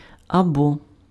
The Ukrainian word is pronounced [ɐˈbɔ]
Uk-або.ogg.mp3